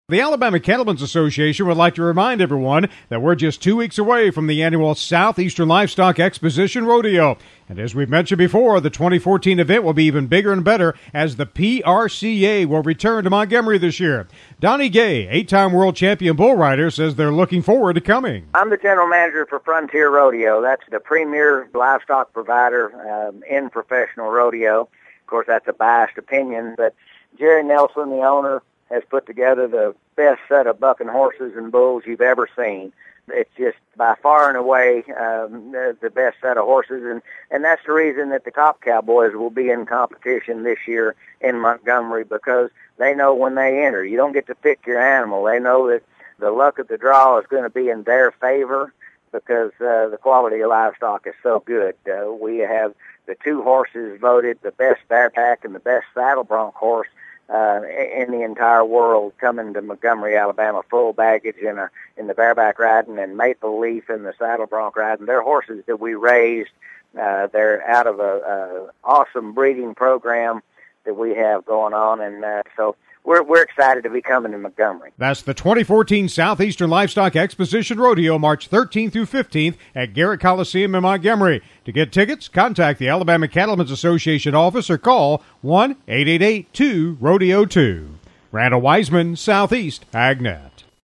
The 2014 Southeastern Livestock Exposition Rodeo is coming March 13-15 at Garrett Coliseum in Montgomery. Donnie Gay, eight time world champion bull rider, talks about the stock they will be bringing to this year’s event.